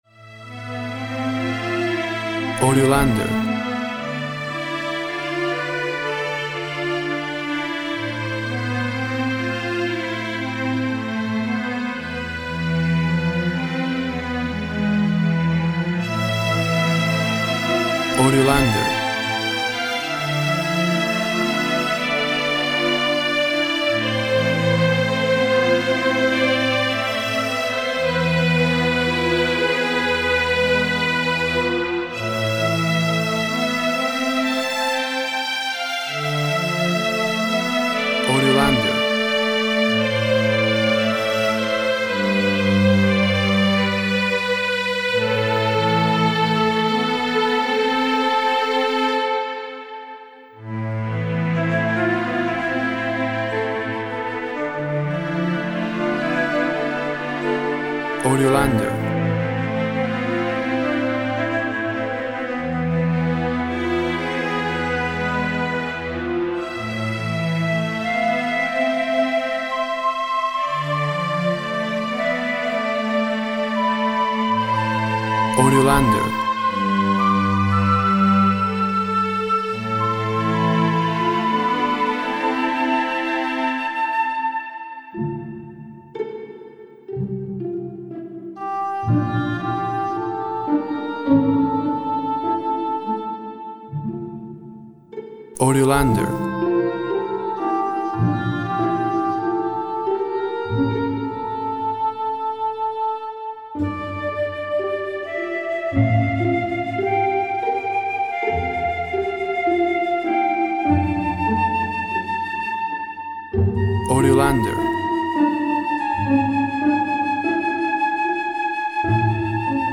Sounds of symphony orchestra plays a mournful work.
Tempo (BPM) 58/52